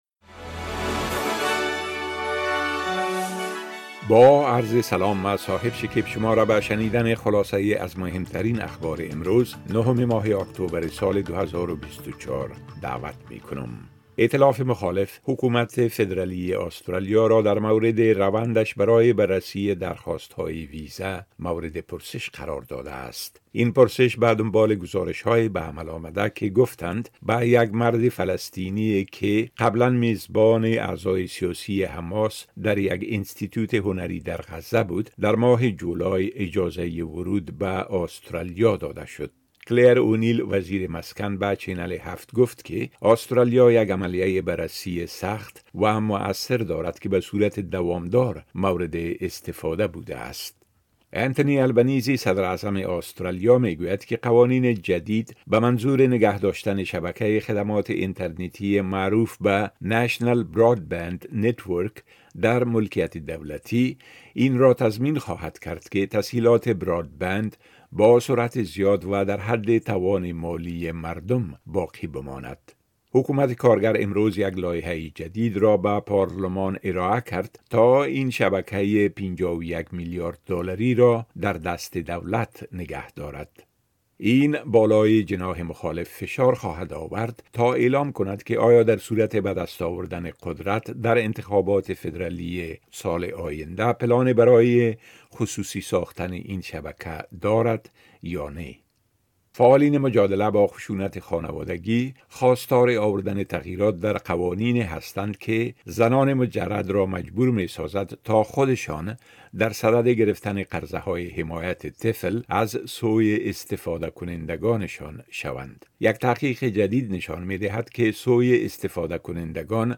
خلاصۀ مهمترين اخبار روز از بخش درى راديوى اس بى اس